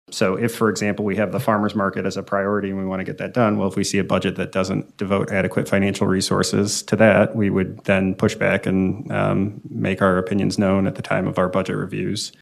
Councilmember Chris Burns says they will be moving into budget deliberations in a couple of months, and they will want to see their goals addressed in that spending plan.